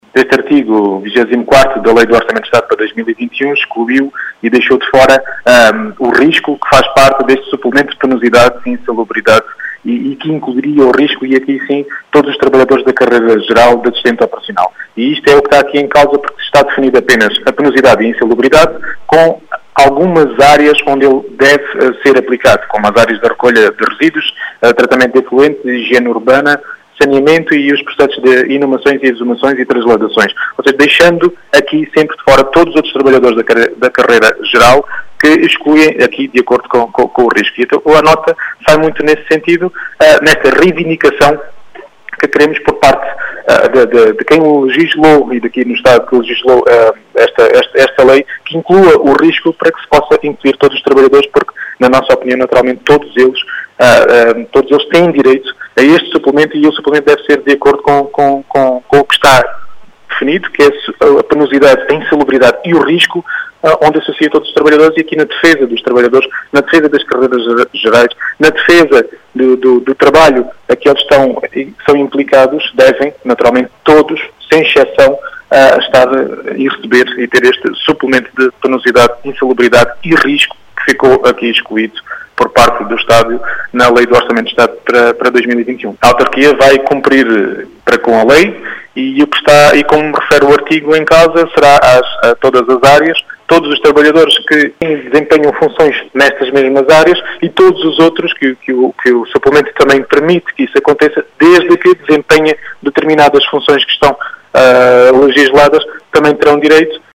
Rui Raposo, presidente da Câmara Municipal de Vidigueira, defende a inclusão do “risco” enquanto suplemento, e afirma que a autarquia “vai cumprir com a lei”.